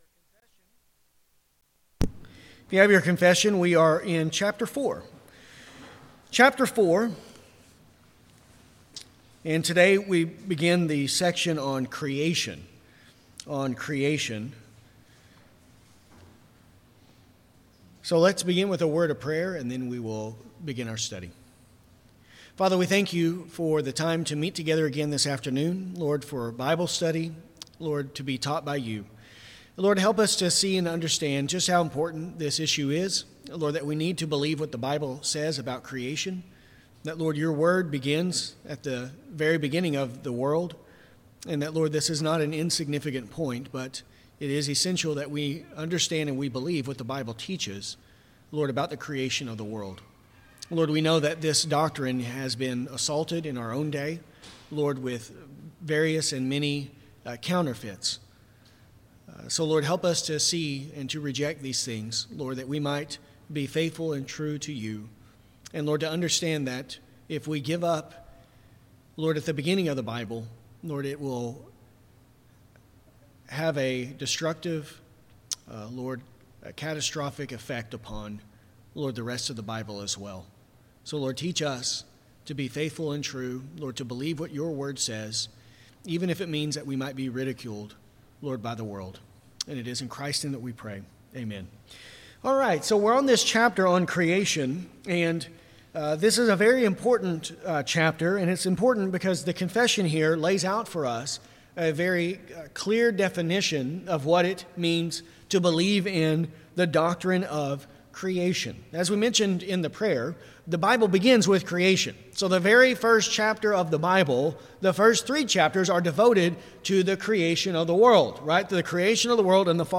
This lesson covers paragraph 4.1 .